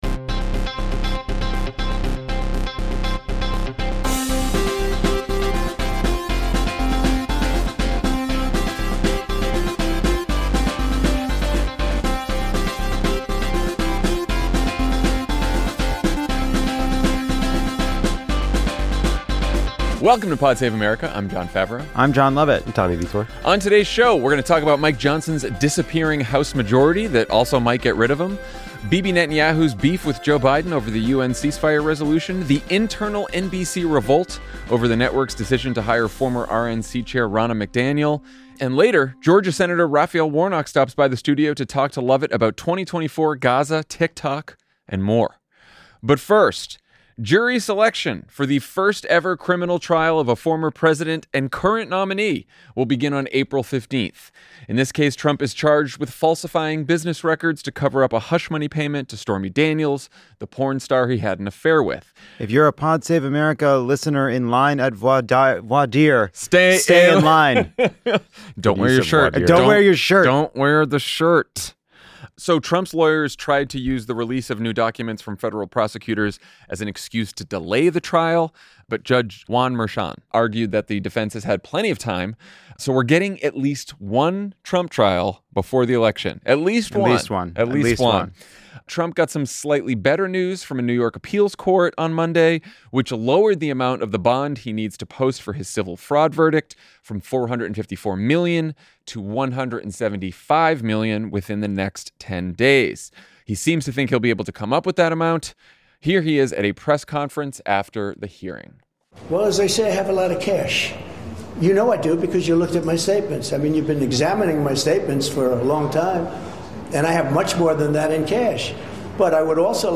And later, Senator Raphael Warnock stops by the studio to talk to Lovett about Georgia, the 2024 campaign, and more.